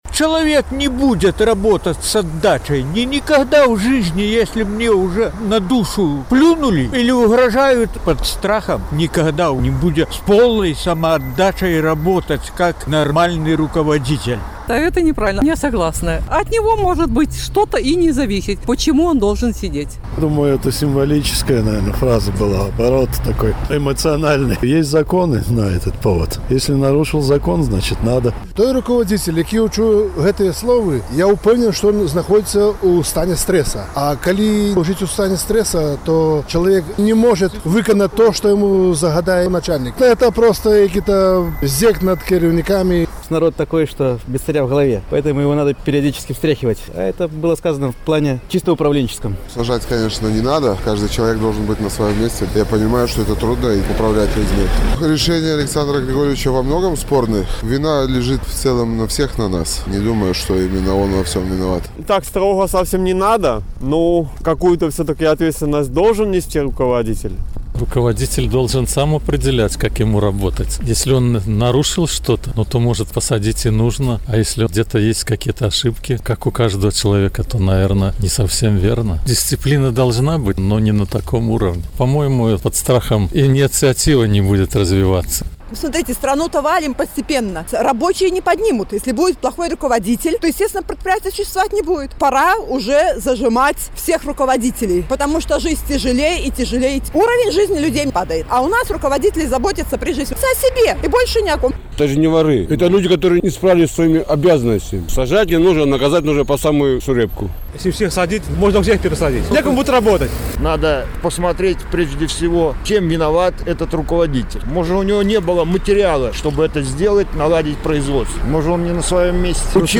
На гэтае пытаньне адказваюць жыхары Гомеля.